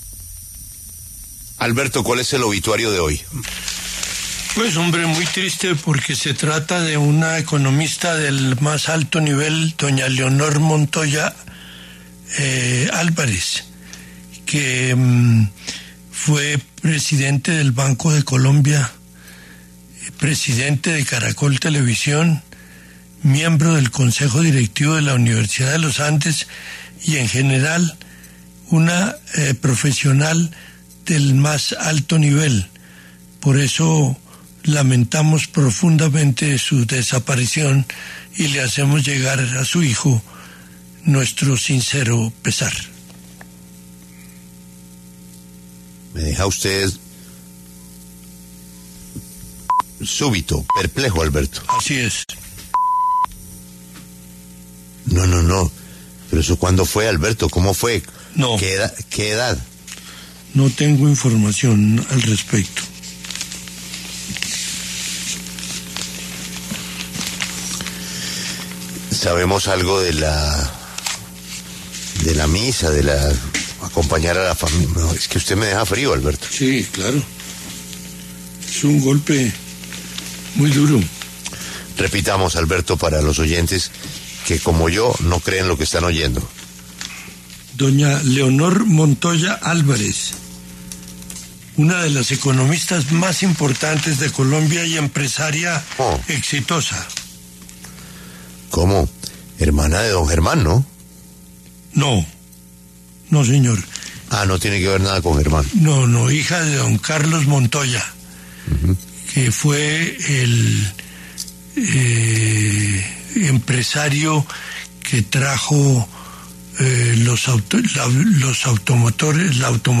Alberto Casas, periodista de W Radio, lamentó la muerte de Leonor Montoya Álvarez y envió sus condolencias a su hijo.